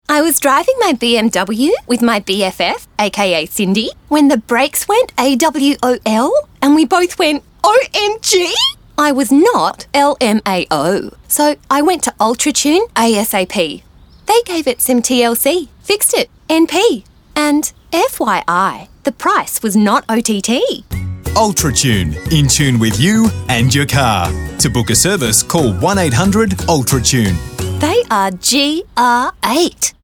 The three radio spots feature three different characters all unsure of what’s gone wrong with their car.